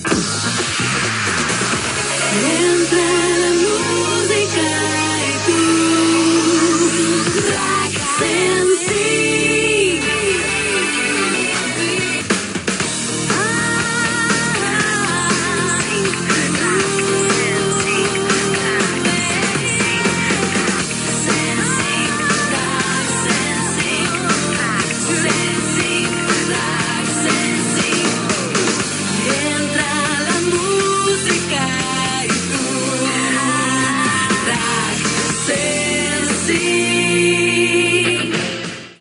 Indicatiu cantat de l'emissora.